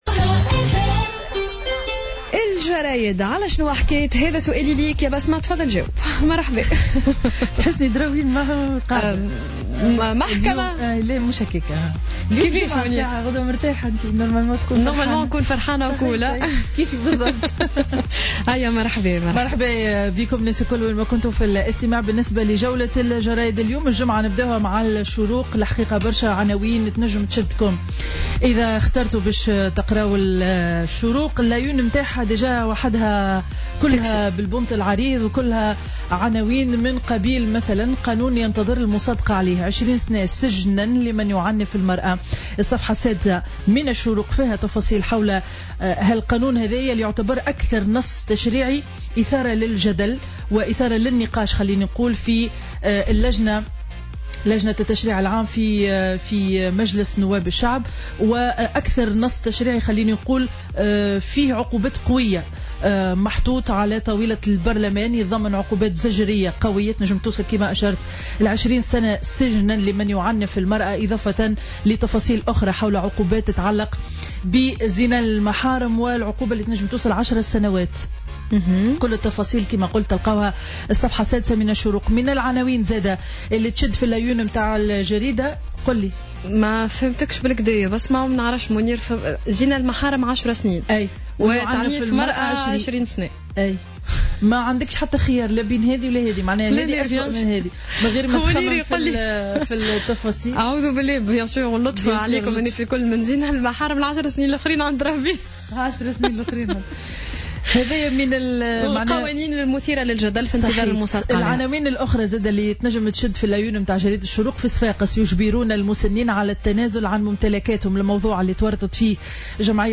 Revue de presse du vendredi 31 mars 2017